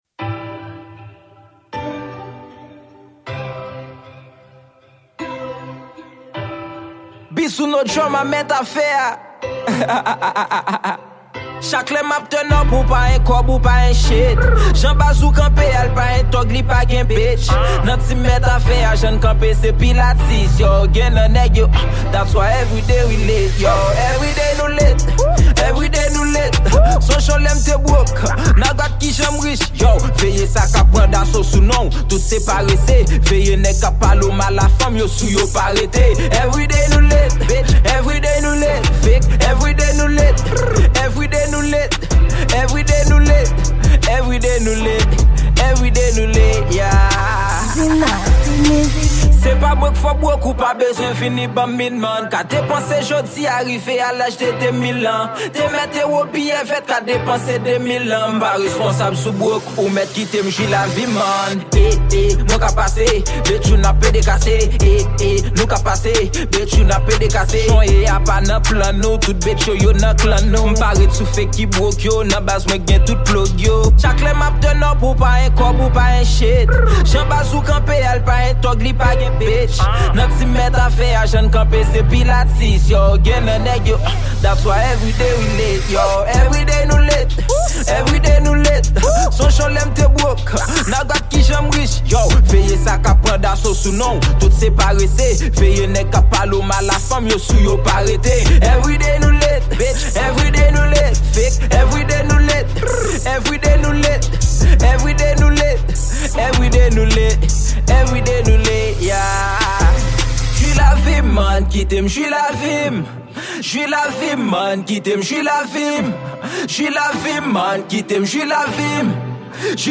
Genre: TraP